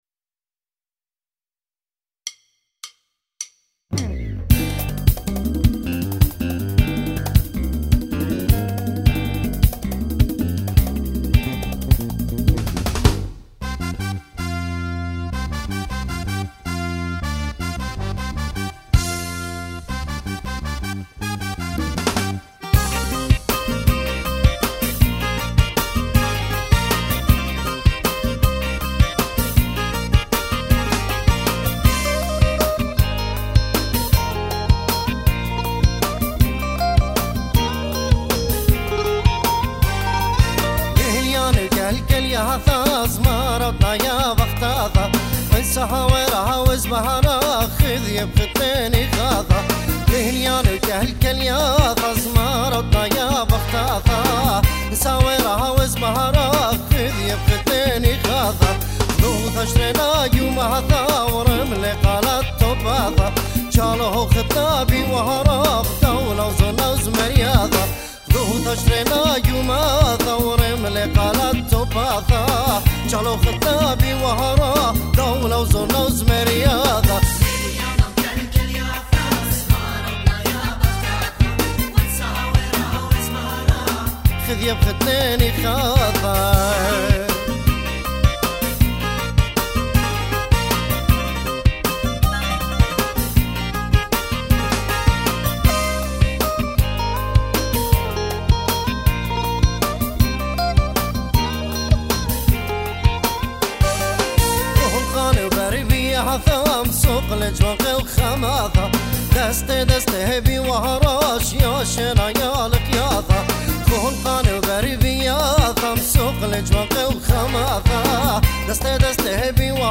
Keyboard sequences
Bass Guitar